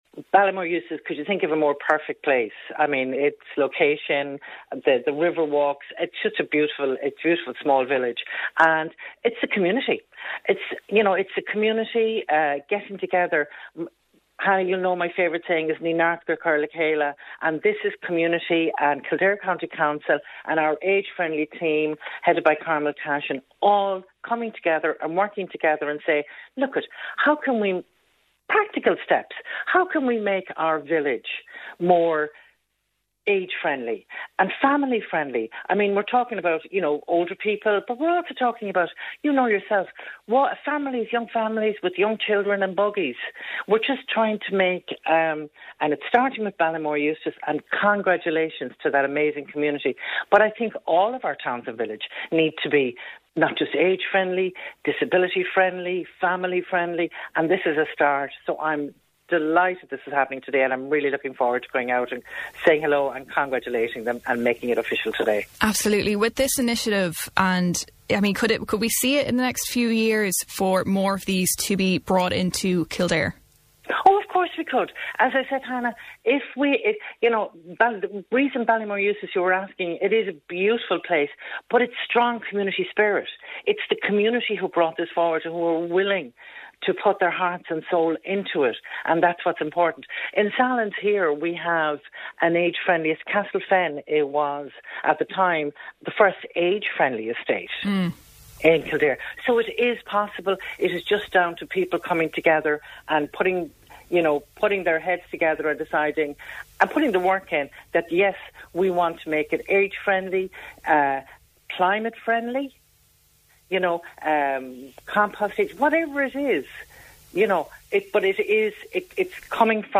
Speaking on Kildare Today,  Cathaoirleach and Councillor for Naas LEA Carmel Kelly said that she couldn't think of a more perfect place for the launch.